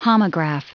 Prononciation du mot homograph en anglais (fichier audio)
Prononciation du mot : homograph
homograph.wav